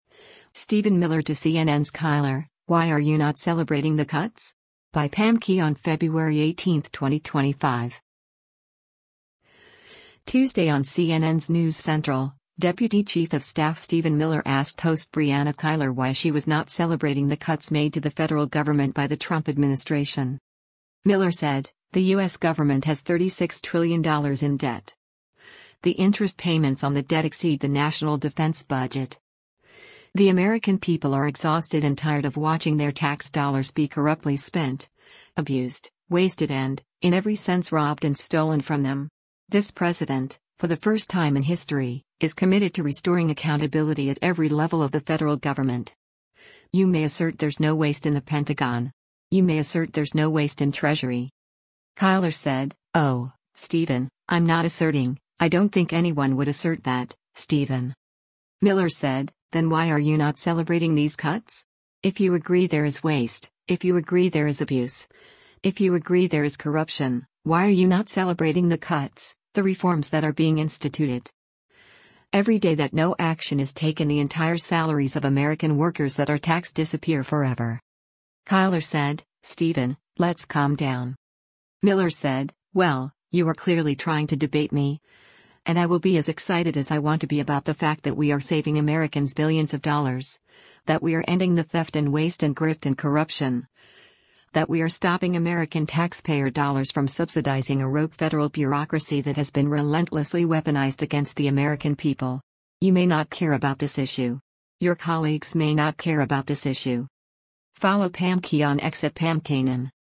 Tuesday on CNN’s “News Central,” Deputy Chief of Staff Stephen Miller asked host Brianna Keilar why she was not “celebrating the cuts” made to the federal government by the Trump administration.